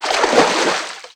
MISC Water_ Splash 06.wav